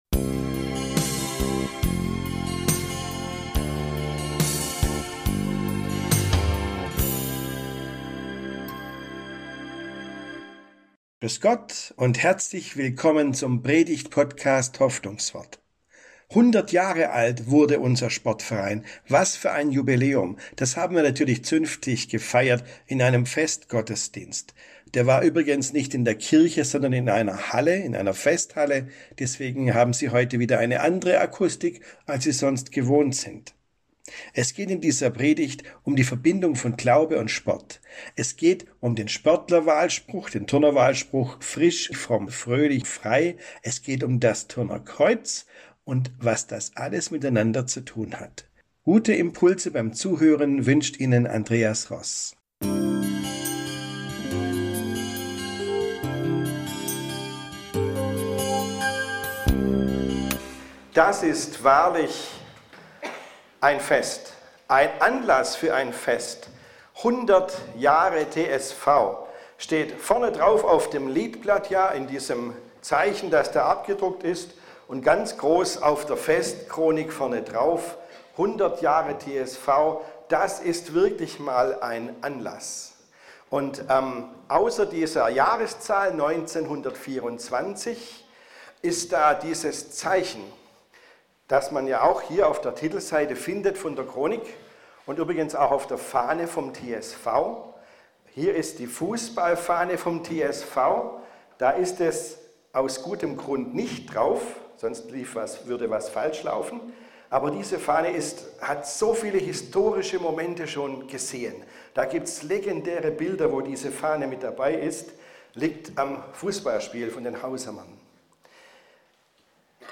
Glaube und Sport: Frisch, fromm, fröhlich, frei ~ Hoffnungswort - Predigten